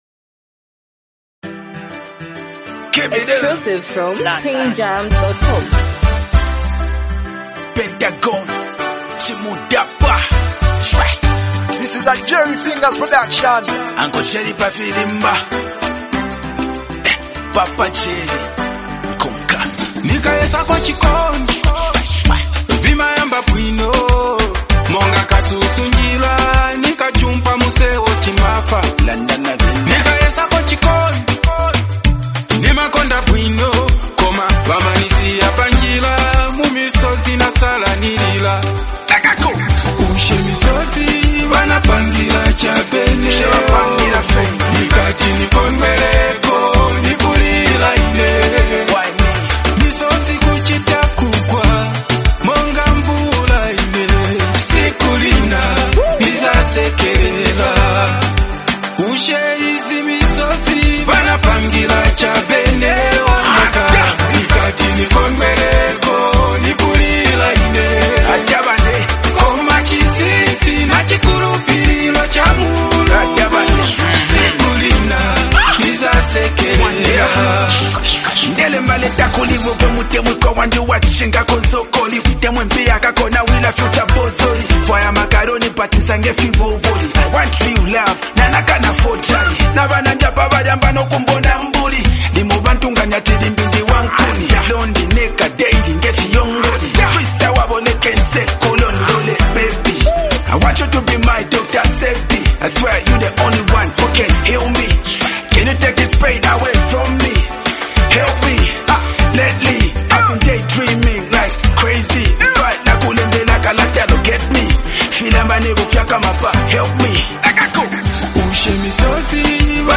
is an emotional and deeply reflective song
delivers heartfelt melodies
adds a powerful rap verse